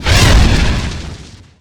horror
Dragon Breath Fire 2